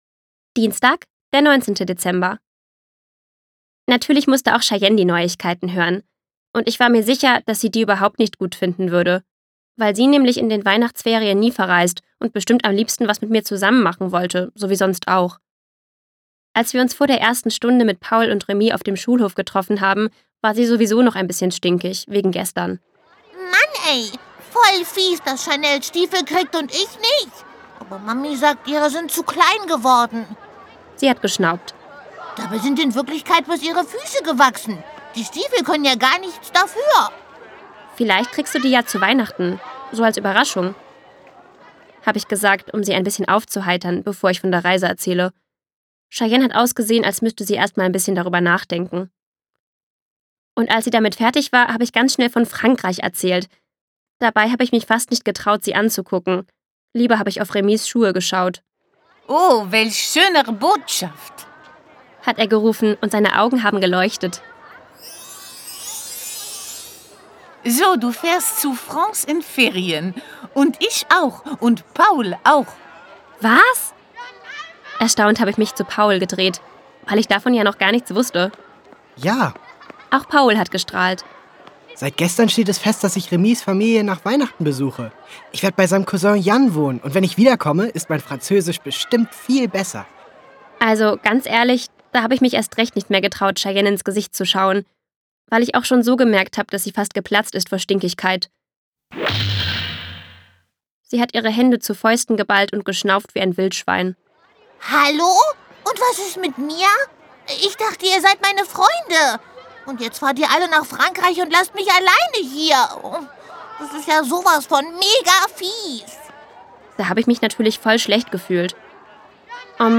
ungekürzte Lesung
Erst recht mit der immer passenden Geräuschkulisse im Hintergrund.
Ich hatte es mit Kopfhörern gehört und hatte mich bei den ersten Geräuschen erst mal erschrocken umgedreht, bis ich kapiert hatte, dass es zum Hörspiel dazu gehört.